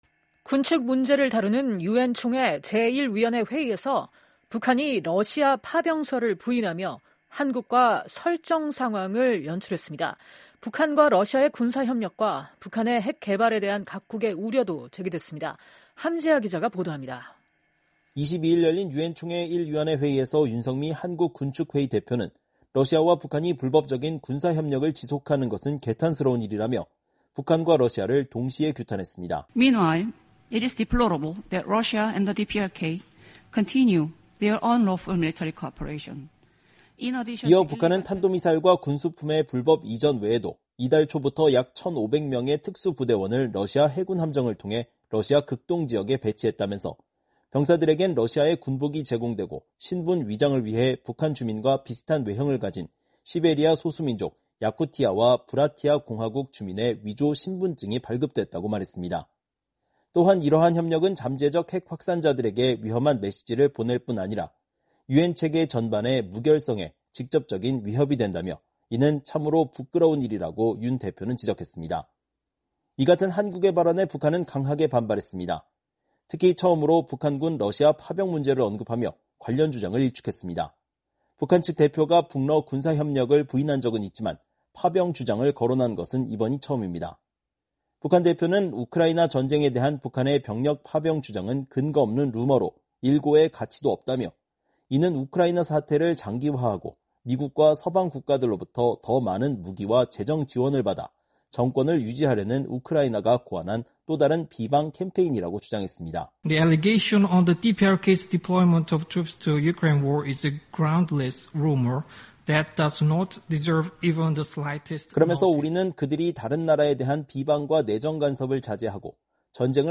22일 미국 뉴욕 유엔본부에서 개최된 유엔총회 제1위원회 회의. (화면출처: UNTV)